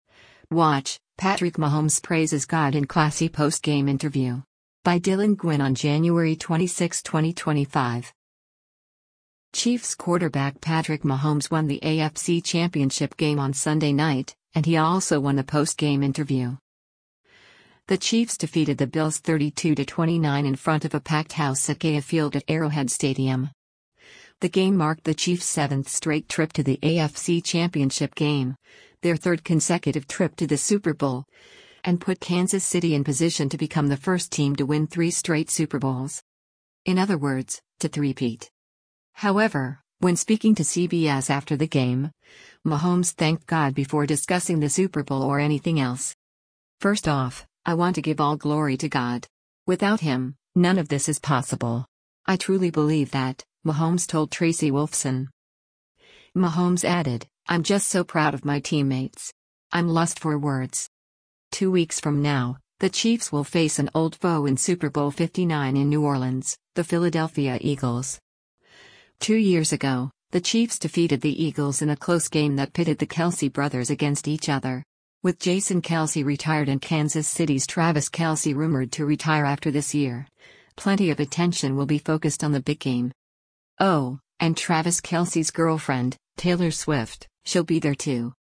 WATCH: Patrick Mahomes Praises God in 'Classy' Postgame Interview
However, when speaking to CBS after the game, Mahomes thanked God before discussing the Super Bowl or anything else.